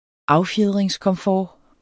Udtale [ ˈɑwˌfjeðˀʁεŋs- ]